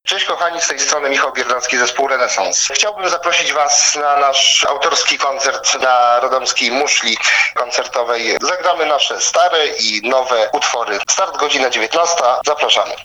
Na koncert zaprasza również wokalista zespołu